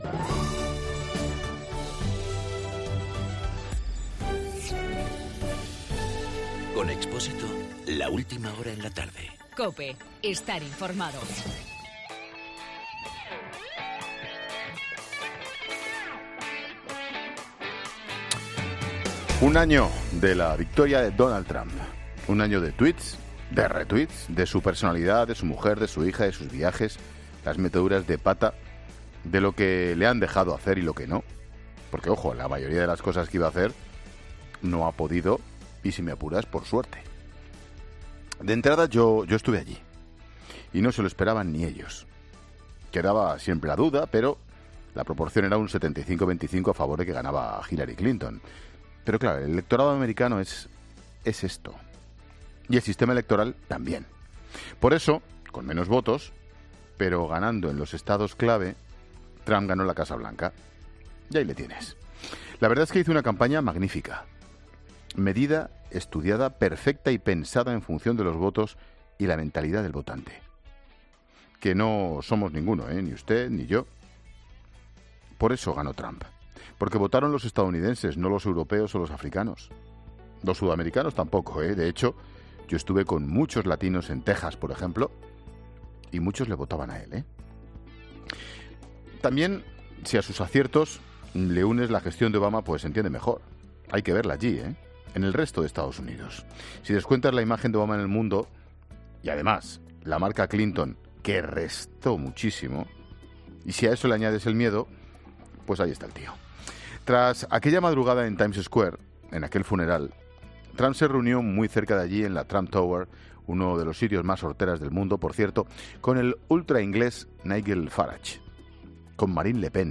AUDIO: El comentario de Ángel Expósito.
Monólogo de Expósito